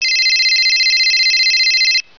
ringing.wav